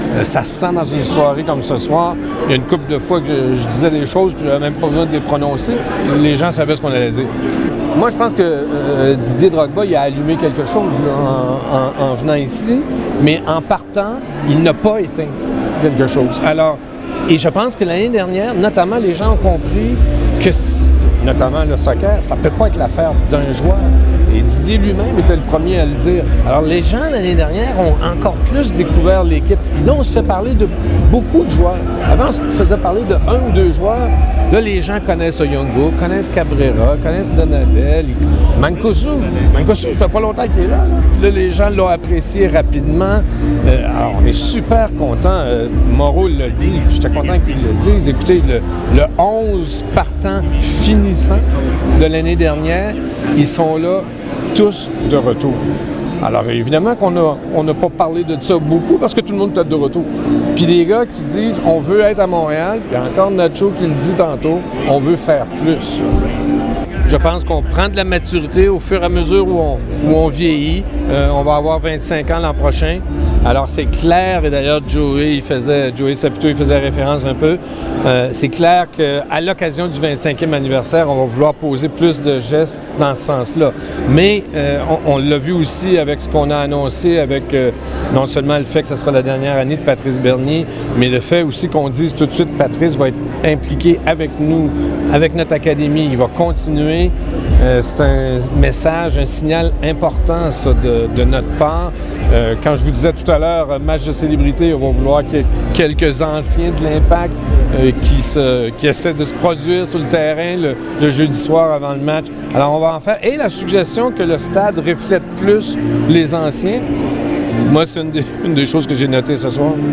Stagione 2017: prospettive e considerazioni dei protagonisti all’assemblea dei membri e presentazione della seconda maglia per la stagione 2017
Le interviste: